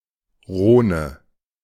Names in other languages include German: Rhone [ˈroːnə]
De-Rhone.ogg.mp3